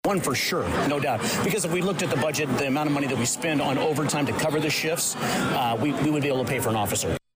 Full Crowd Comes to St. Mary’s Church for Westville Candidates Forum